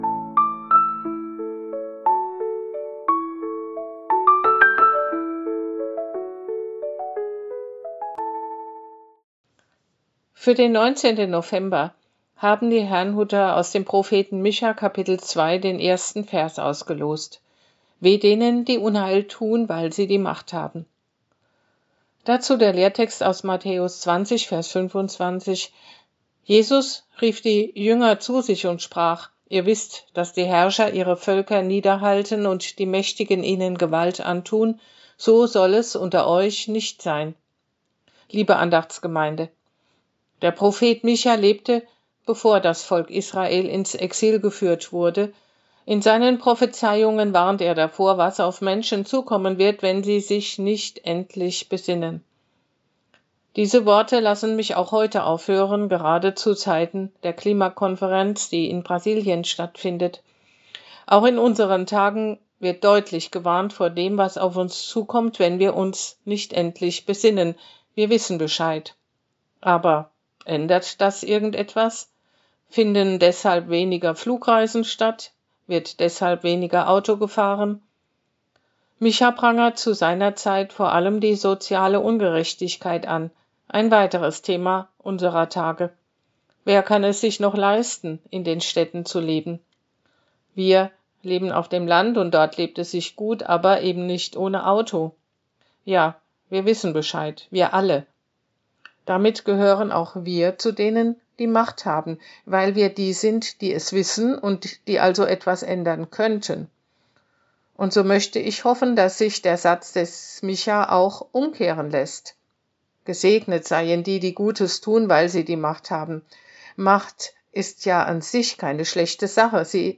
Losungsandacht für Mittwoch, 19.11.2025